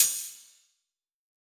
soft-hitnormal.wav